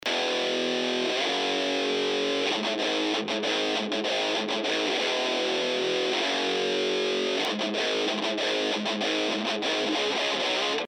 このギターの録音データですと矢印のつなぎ目の部分でプツッと音が鳴るわけなんですよね。
実際の音はこちら。（7秒ぐらいの箇所でプツッと鳴っています。）
なんとも嫌な音が鳴っていますよね。